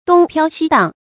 东飘西荡 dōng piāo xī dàng
东飘西荡发音
成语注音 ㄉㄨㄙ ㄆㄧㄠ ㄒㄧ ㄉㄤˋ